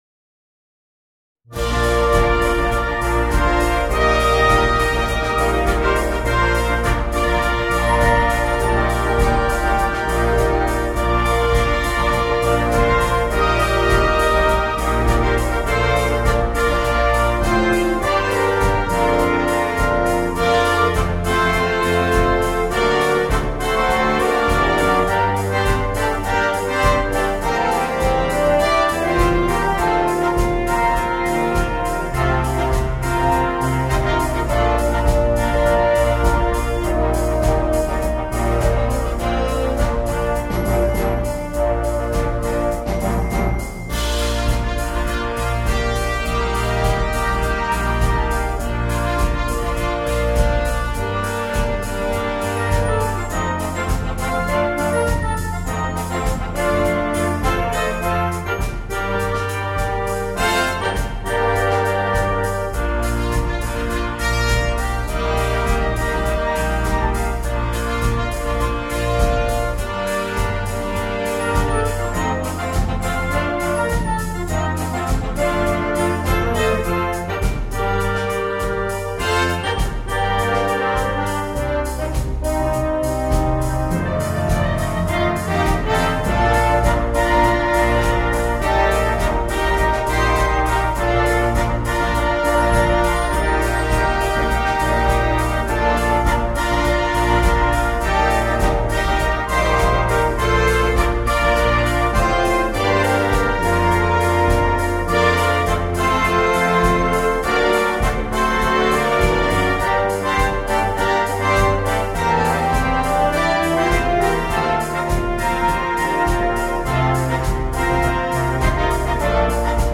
для духового оркестра.